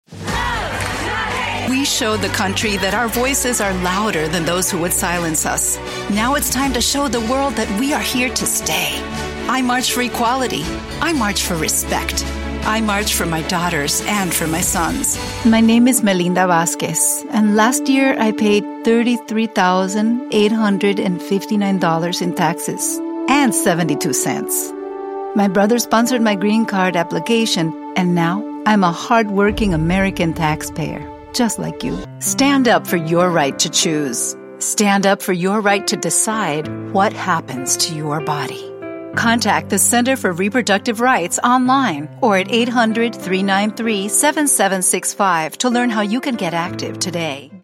Anuncios políticos
Mis clientes describen mejor mi voz como amigable, chispeante, expresiva, agradable, cálida y entusiasta.